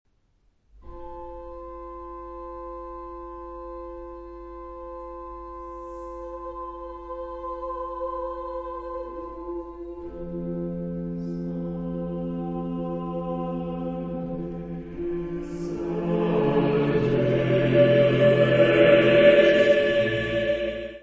Music Category/Genre:  Classical Music
for four voices and organ.